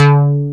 MKSBASS1.wav